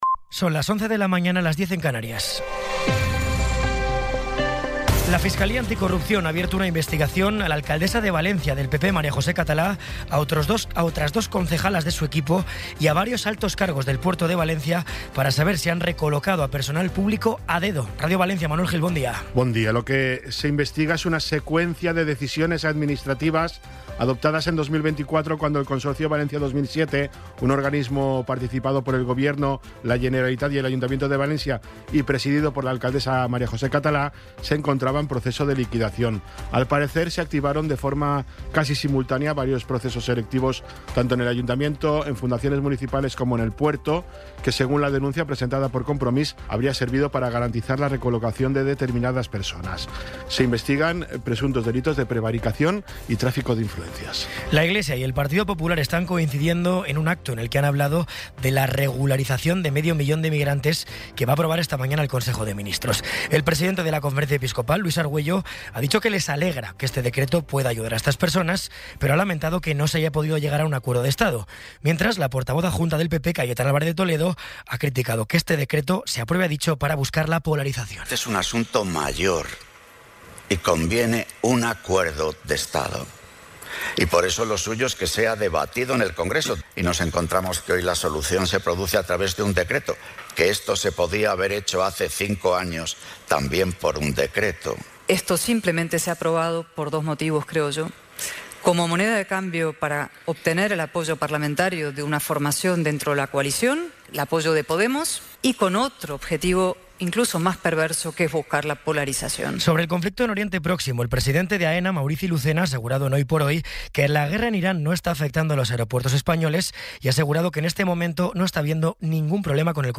Resumen informativo con las noticias más destacadas del 14 de abril de 2026 a las once de la mañana.